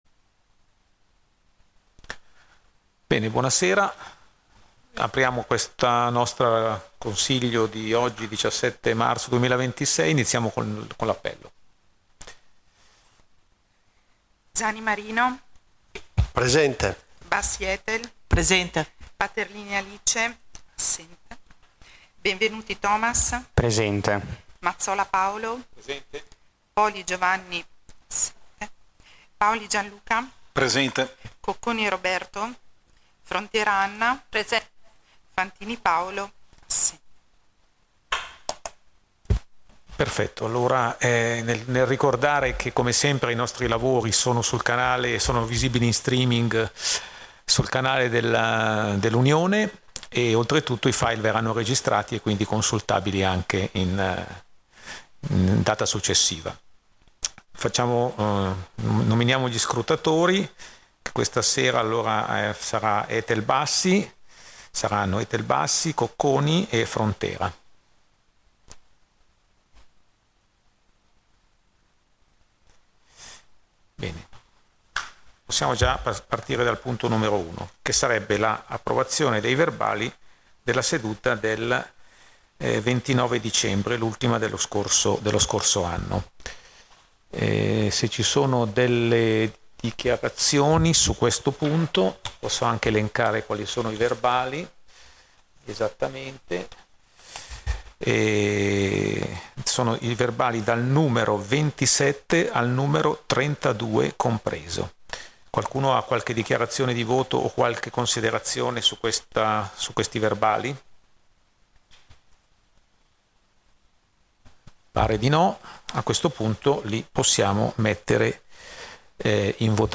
Seduta del Consiglio Unione del 17/03/2026